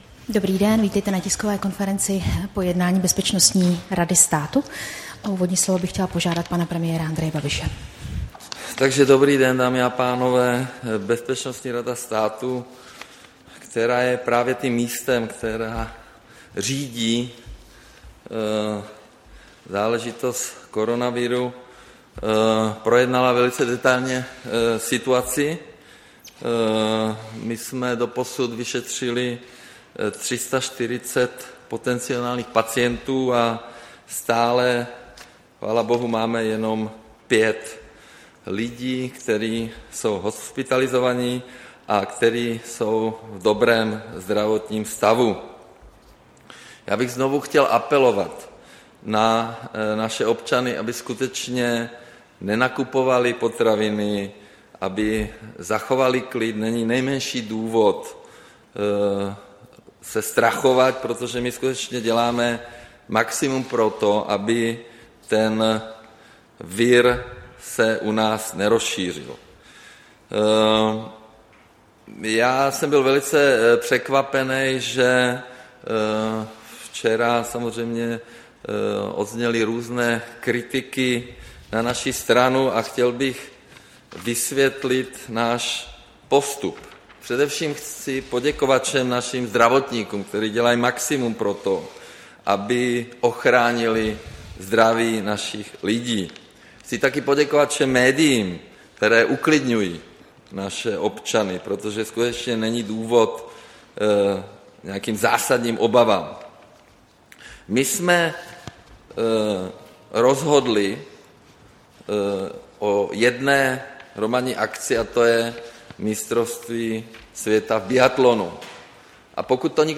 Tisková konference po jednání Bezpečnostní rady státu, 4. března 2020